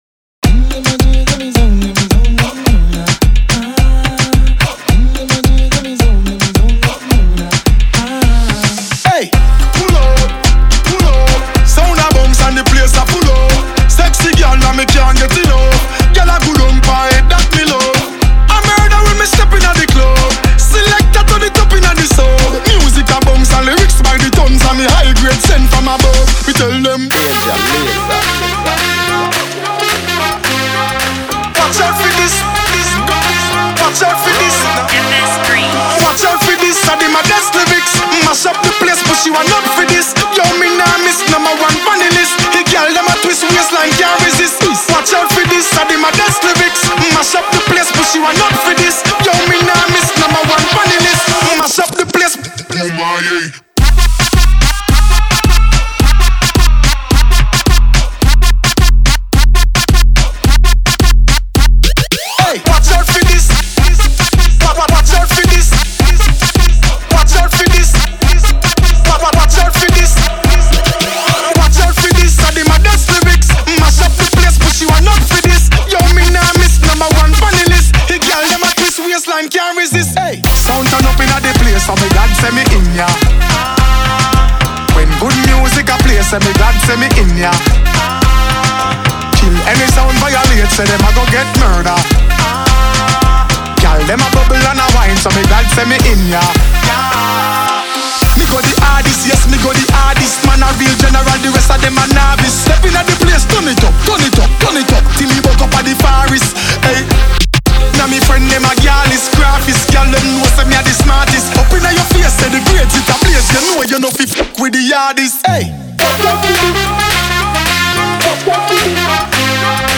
энергичный трек